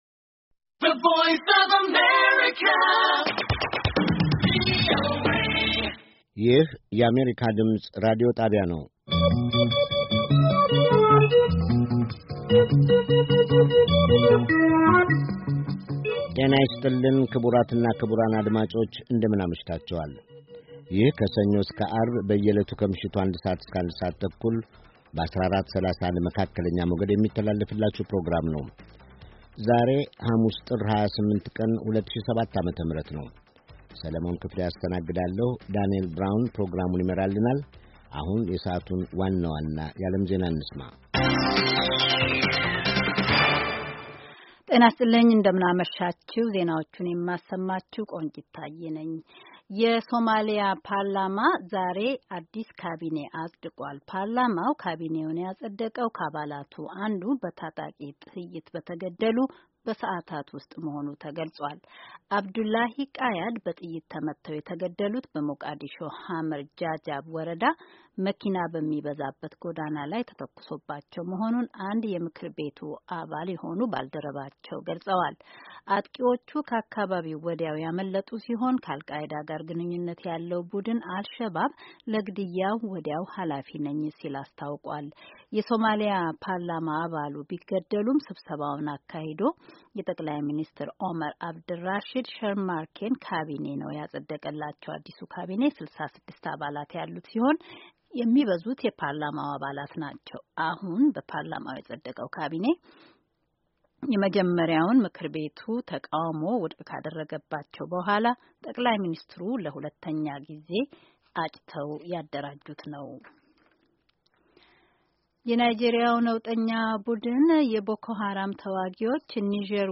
Early edition of Amharic News